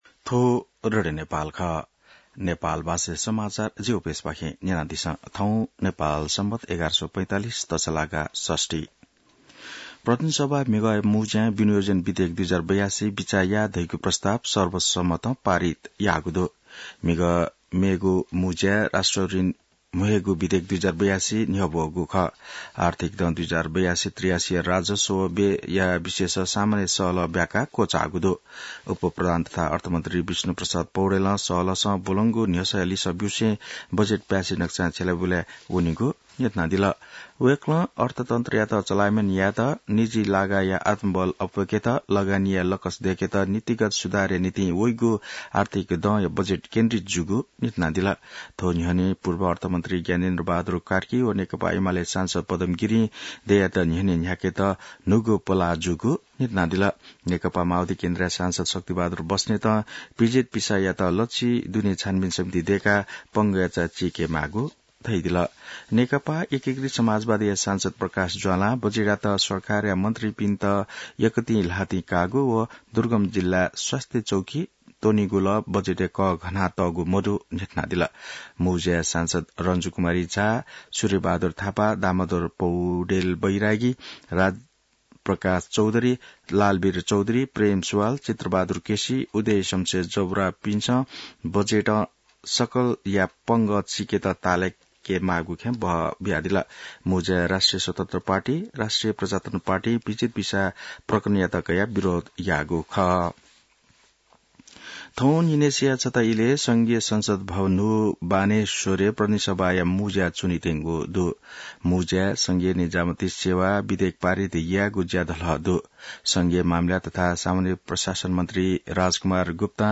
नेपाल भाषामा समाचार : ३ असार , २०८२